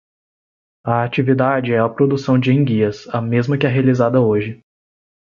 Pronúnciase como (IPA)
/a.t͡ʃi.viˈda.d͡ʒi/